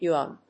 音節yu・an 発音記号・読み方
/júːən(米国英語), ju:ˈɑ:n(英国英語)/